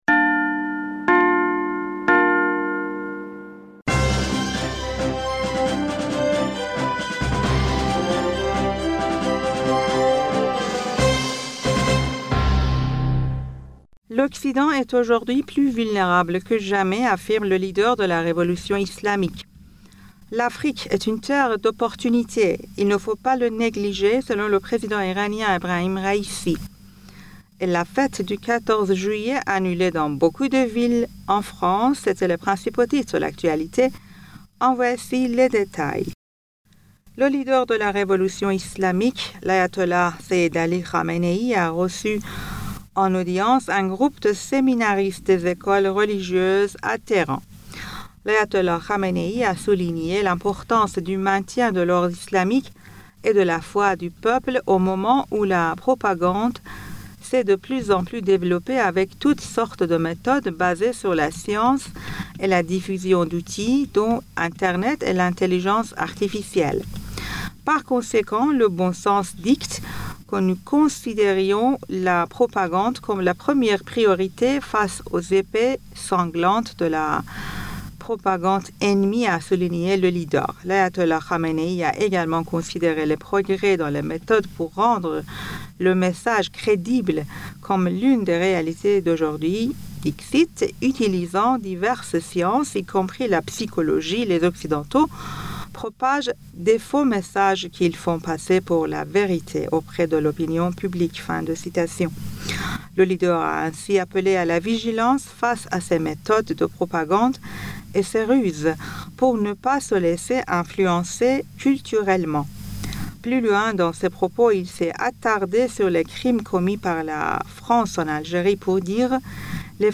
Bulletin d'information du 14 Juillet 2023